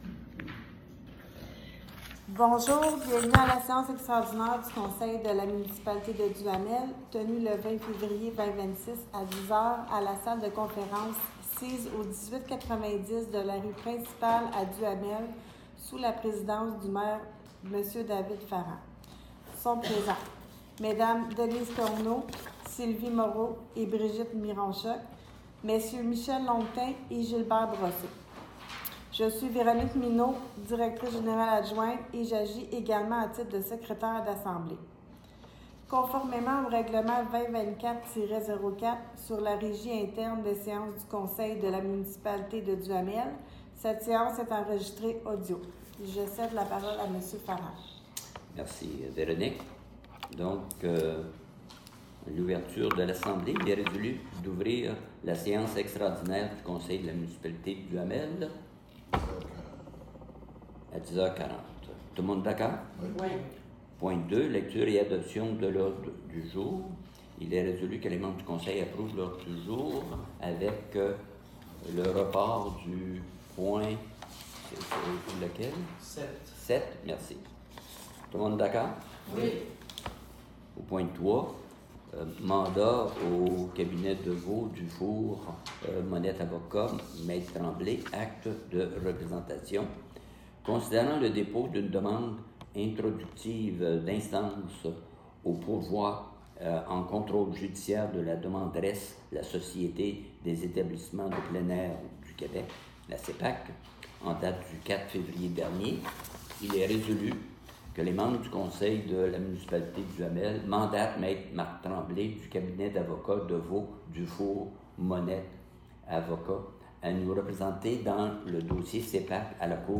Séances du conseil